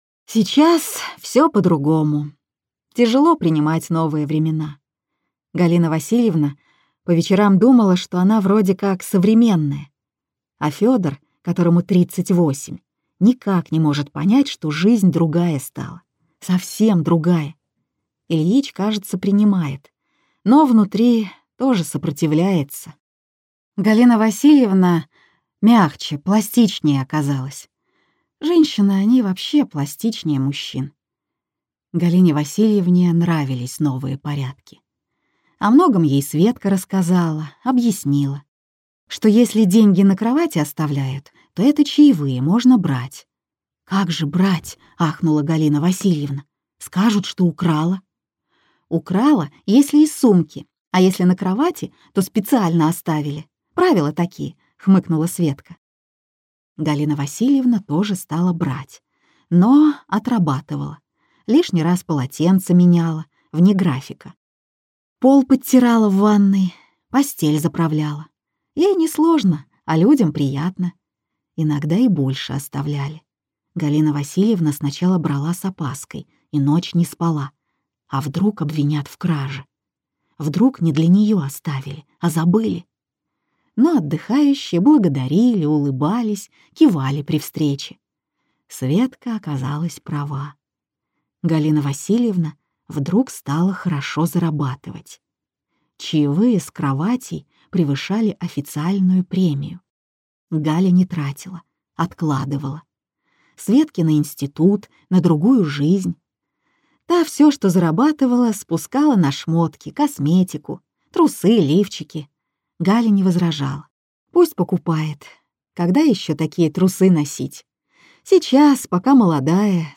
Аудиокнига Уважаемые отдыхающие!